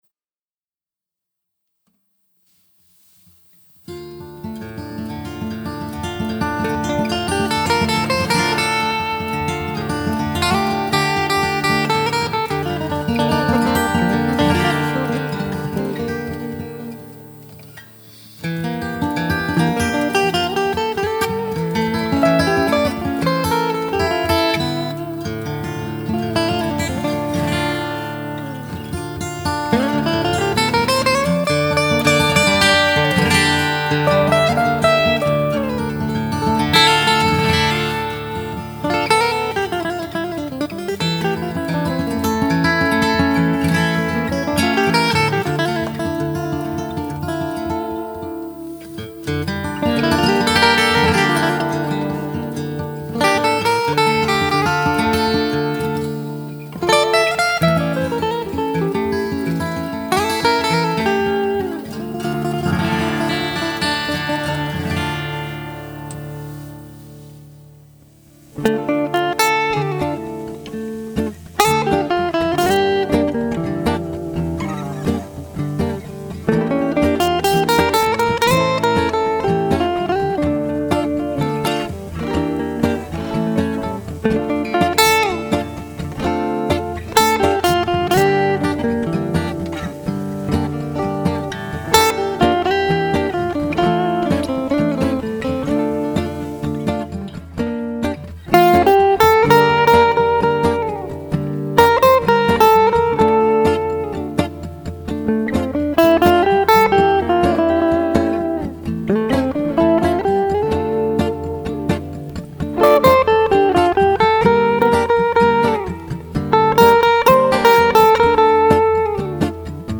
guitar wide open music easy listening
derzeit arbeite ich an aufnahmen für eine neue cd mit auschließlich instrumentaler meditativer akustischer "laid back" musik. neue stücke werden sich  mit früheren stücken von mir abwechseln.
"für dich" (vorabversion mit der henning doderer-jumbo gespielt)